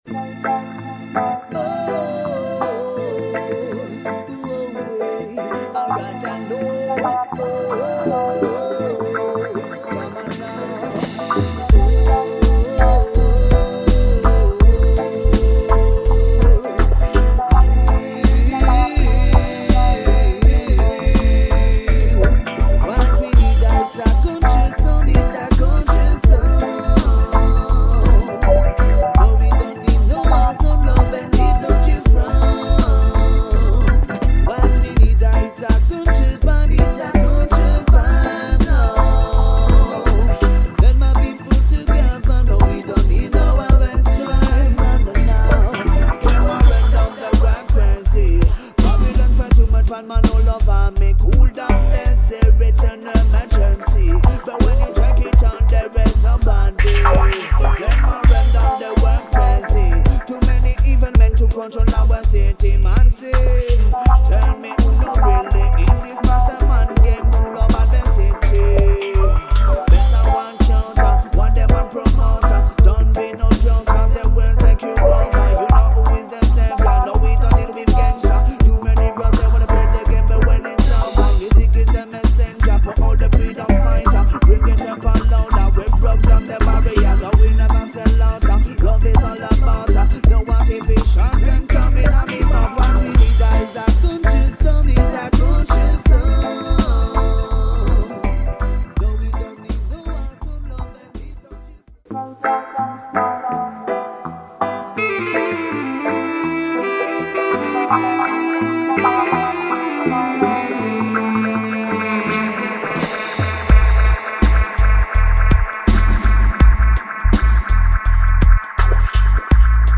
all instrument and drum machine arrangement